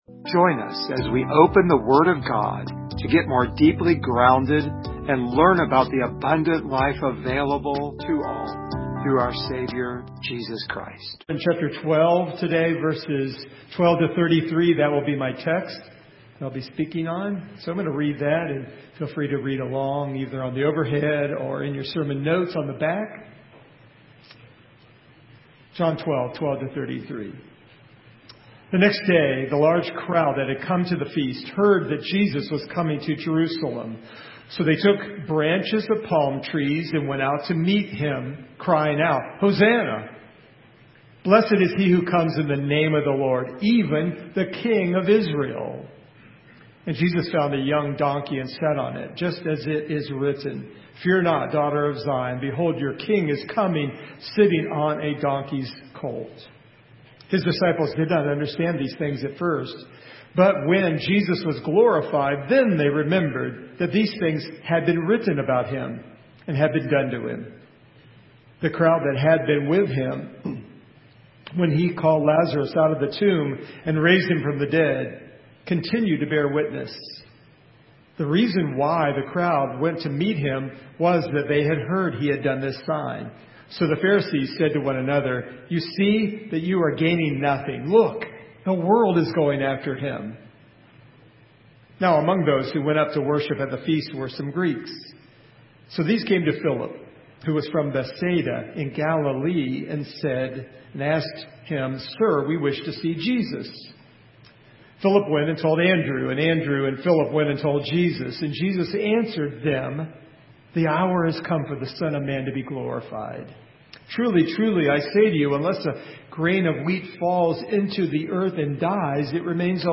John 12:12-33 Service Type: Sunday Morning We see 4 aspects of Jesus' heart in this passage. Topics: Christian Life , Sanctification , The Cross share this sermon « Spiritual Motivations Saturated Or Just Soggy?